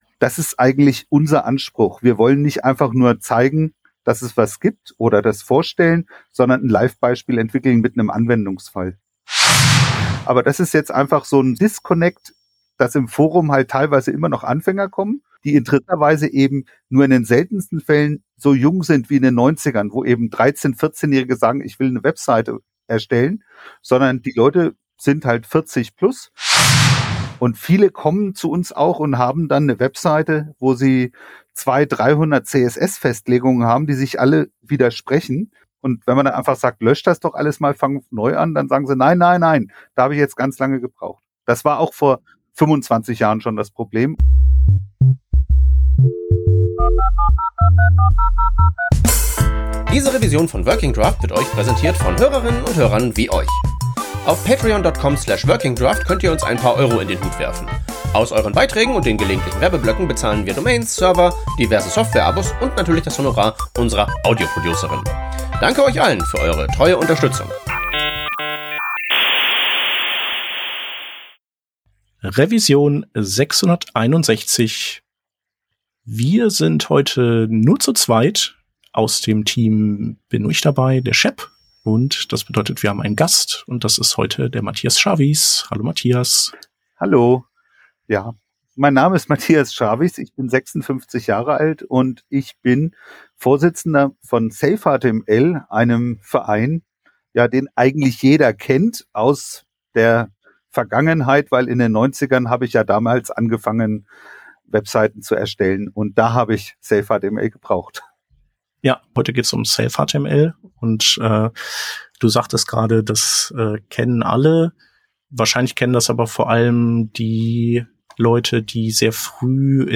Wöchentlicher Podcast für Frontend Devs, Design Engineers und Web-Entwickler:innen